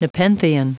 Prononciation du mot nepenthean en anglais (fichier audio)
Prononciation du mot : nepenthean
nepenthean.wav